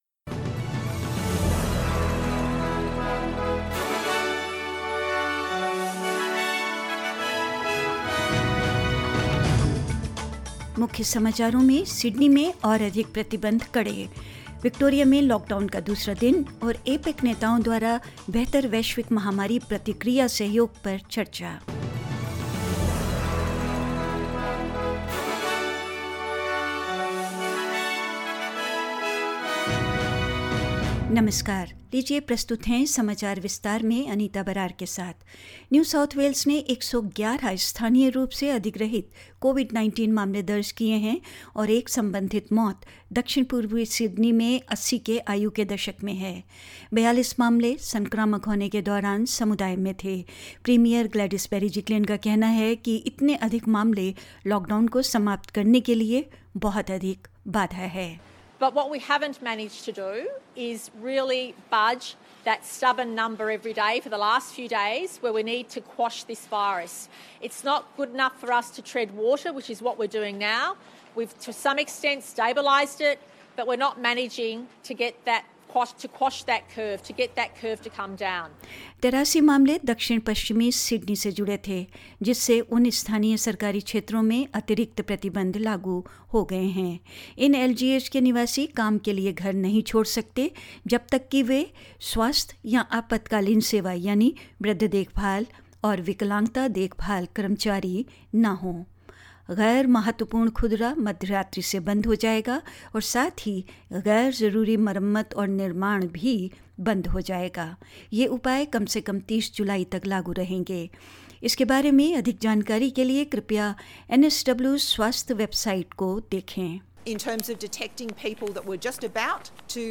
In this latest SBS Hindi News bulletin of Australia and India: New South Wales has recorded 111 locally acquired COVID-19 cases and restrictions tighten in Sydney as cases rise further while Victoria's lockdown continues; APEC leaders meet virtually to discuss better global pandemic response co-operation; India reports 38079 new COVID - 19 infection cases and more news